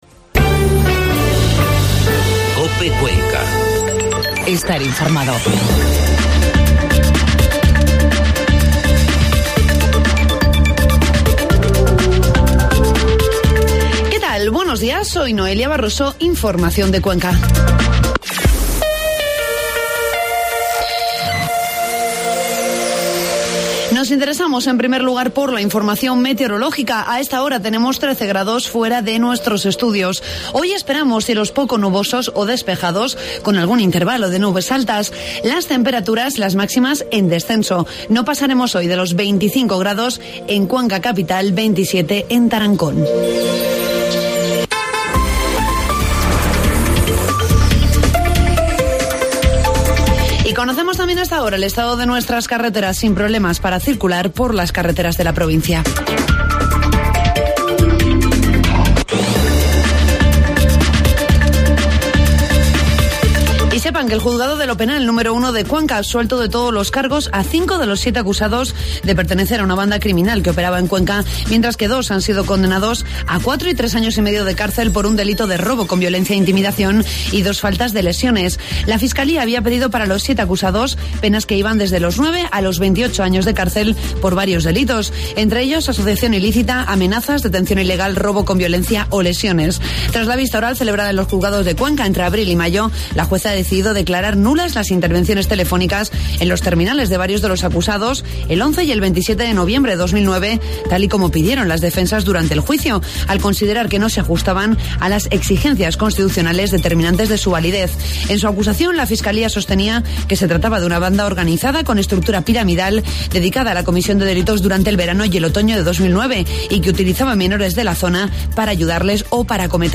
informativo matinal COPE Cuenca 12 de septiembre